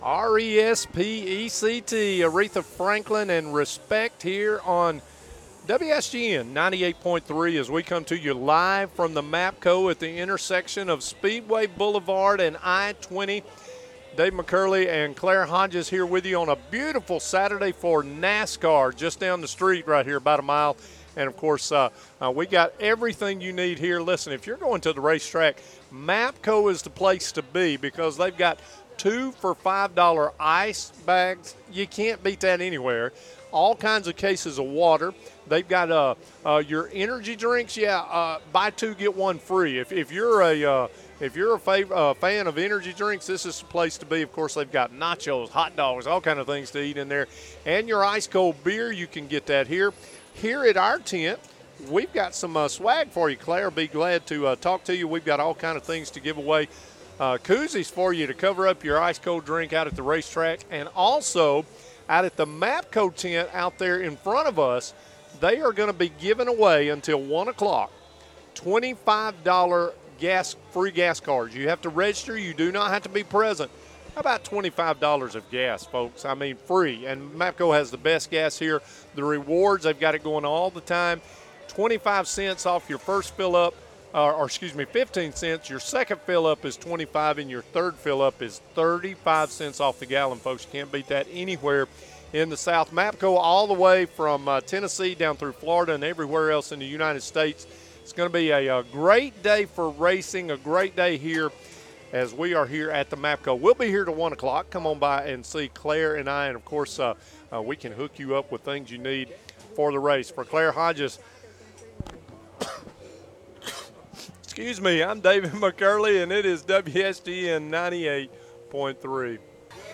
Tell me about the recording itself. Live from the MAPCO on Speedway Blvd. - Day 2